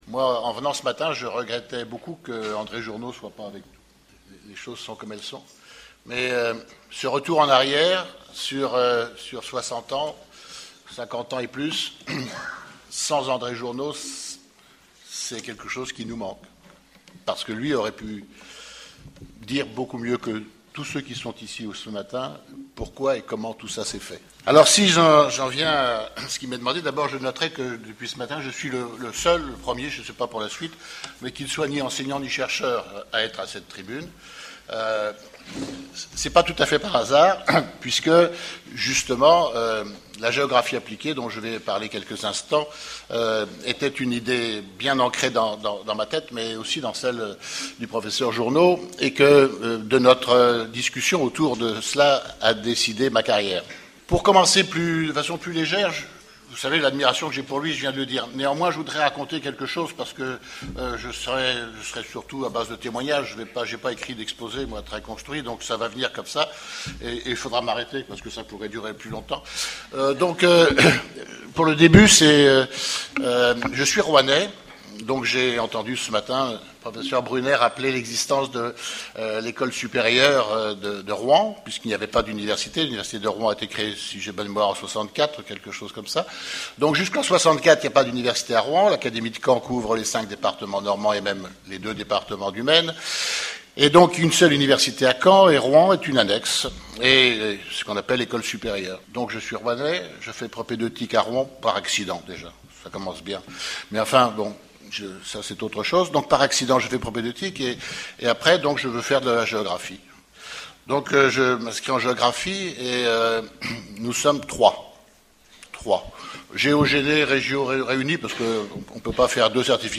Ce témoignage sur les premières années de l'institut de géographie à Caen et les balbutiements de la géographie appliquée a été enregistré le 27 novembre 2008, lors de la journée d'étude intitulée Les géographes mettent cartes sur table : histoire et mémoire de la géographie universitaire à Caen, organisée par l’UFR de géographie.